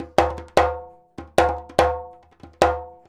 100DJEMB06.wav